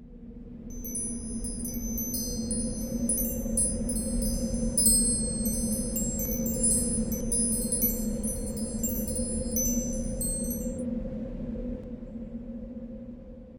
windchime.ogg